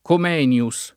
vai all'elenco alfabetico delle voci ingrandisci il carattere 100% rimpicciolisci il carattere stampa invia tramite posta elettronica codividi su Facebook Comenio [ kom $ n L o ] (lat. Comenius [ kom $ n L u S ]) cogn.